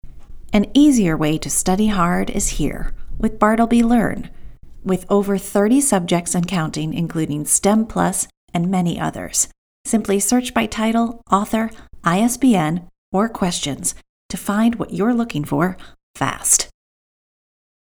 Education Service Commercial US